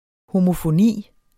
homofoni substantiv, fælleskøn Bøjning -en Udtale [ homofoˈniˀ ] Oprindelse af homo- og græsk phone 'lyd', dvs.